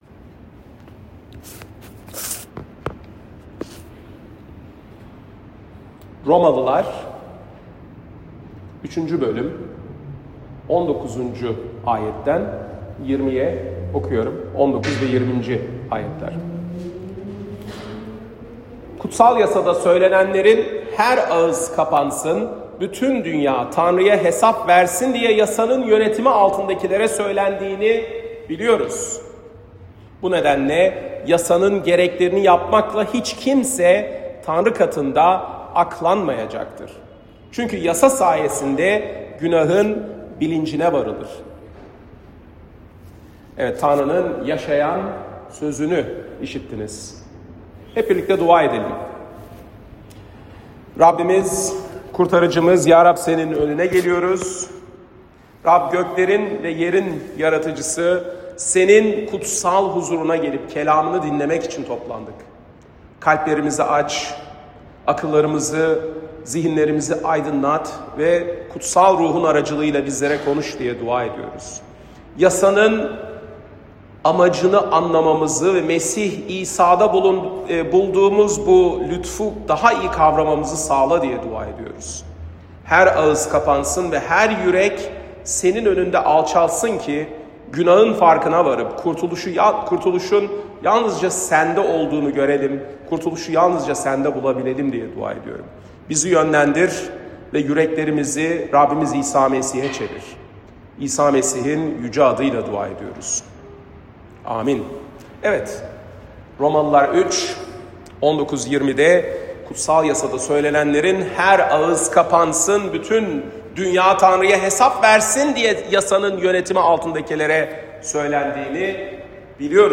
Salı, 1 Nisan 2025 | Romalılar Vaaz Serisi 2024-26, Vaazlar